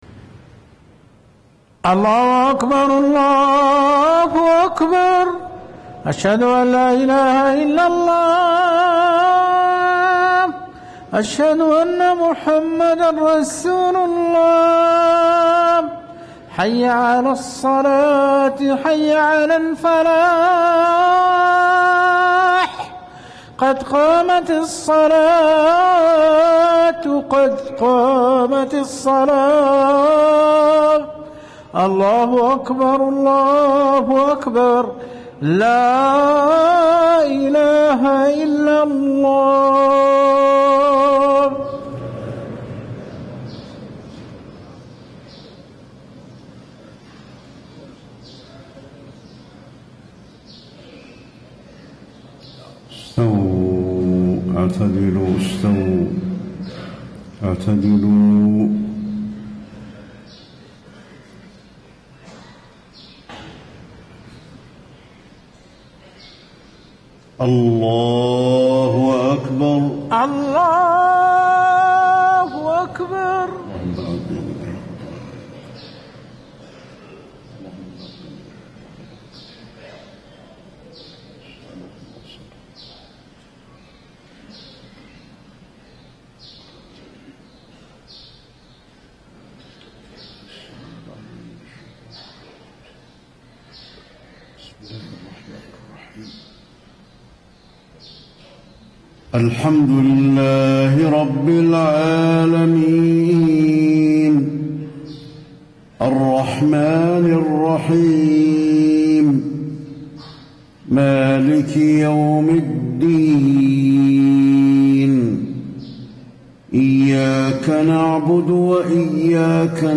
صلاة الفجر 7-6-1435 ما تيسر من سورة الزمر > 1435 🕌 > الفروض - تلاوات الحرمين